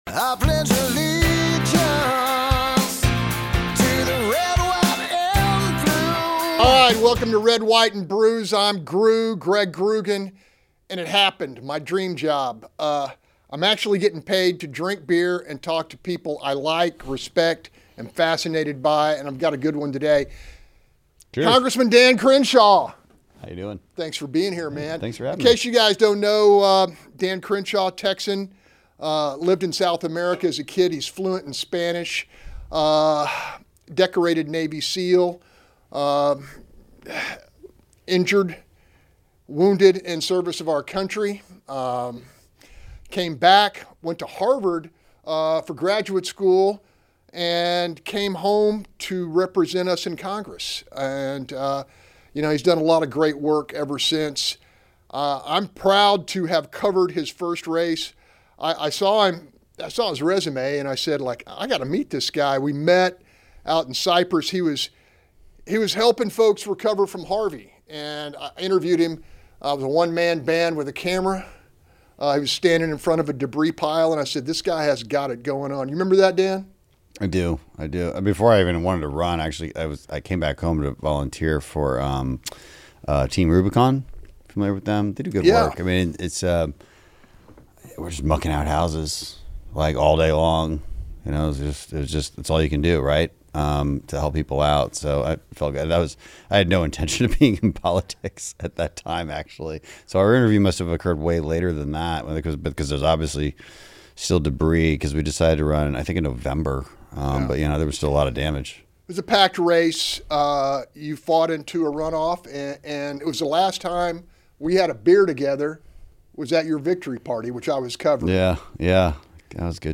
talks with U.S. Rep. Dan Crenshaw